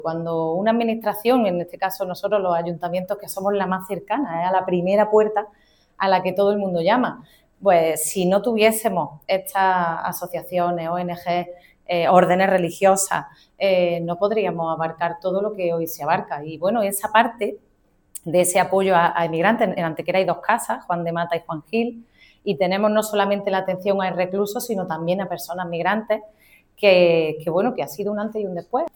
El acto se ha celebrado en el Salón El Palomar de El Pimpi, un espacio emblemático de la capital malagueña, y ha contado con la participación de la teniente de alcalde Ana Cebrián, quien ha estado acompañada por el teniente de alcalde José Manuel Fernández y los concejales José Medina y Paqui Sánchez.
Cortes de voz